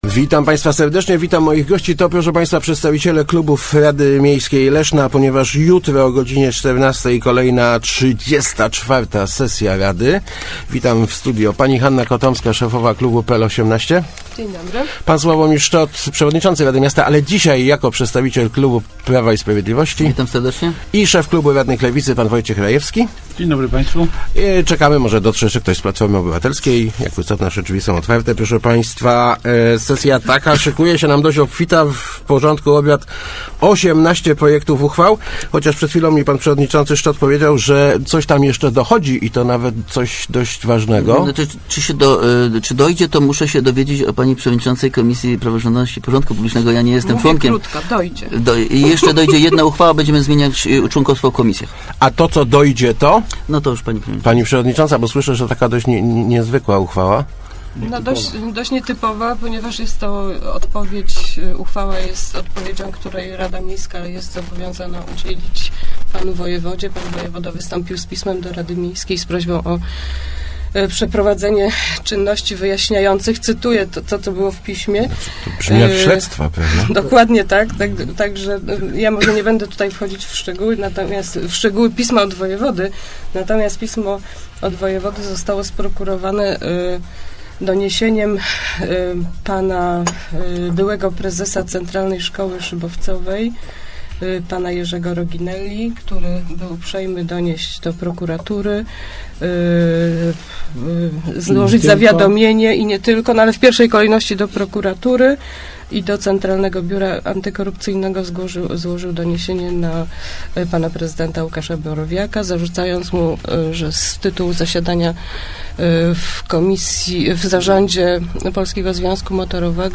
Odpowied� na pismo wojewody w sprawie mandatu prezydenta Borowiaka, "leszczy�skie becikowe" i Kontenery Kultury to tylko niektóre tematy, którymi zajm� si� radni Leszna na czwartkowej sesji. W Rozmowach Elki dyskutowali o nich przedstawiciele klubów RM: Hanna Kotomska z PL18, S�awomir Szczot z PiS i Wojciech Rajewski z Lewicy.